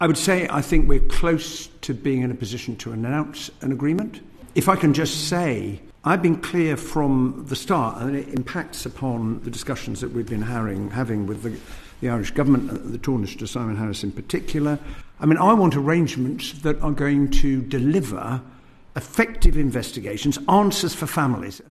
Northern Ireland Secretary of State Hillary Benn’s was questioned on the timeline today: